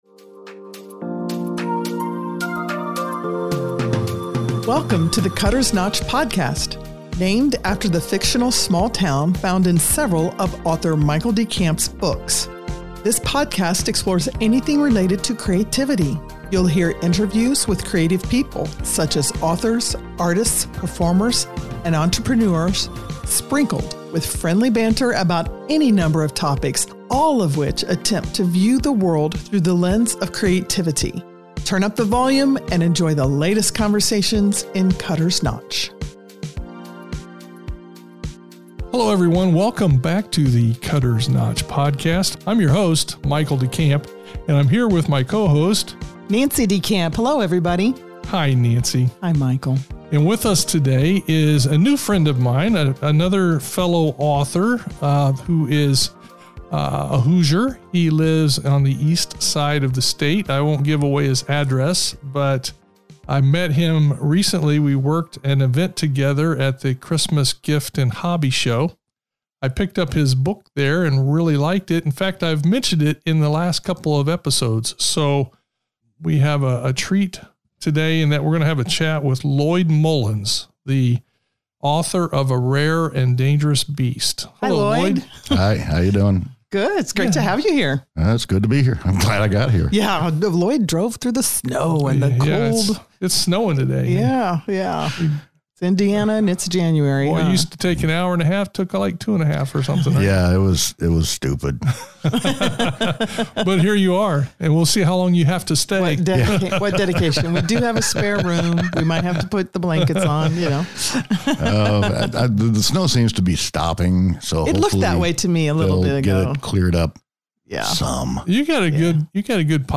It was a fun conversation.